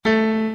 FueraDeEscala/Assets/Game Kit Controller/Sounds/Piano Keys C Scale New/a1.wav at 650a26e6d19b7ddba440527b46677e39e251b958